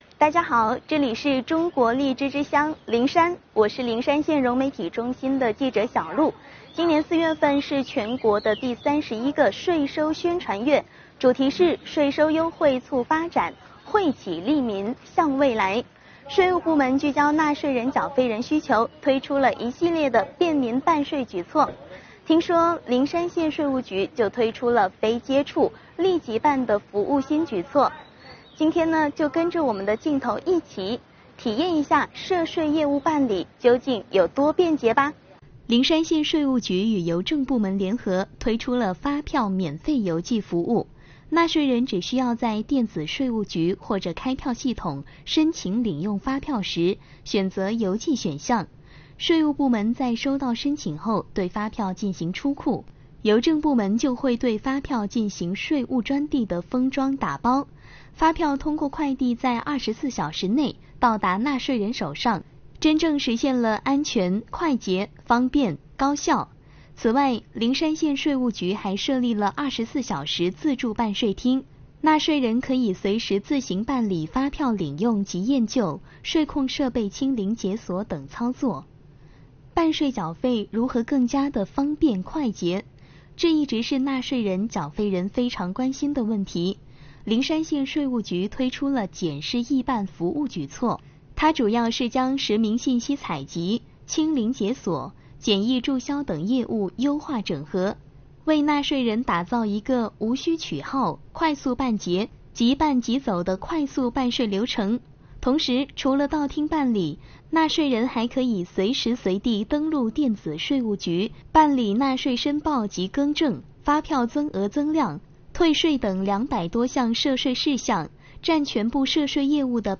【媒体看税收】融媒体记者带您体验“荔即办”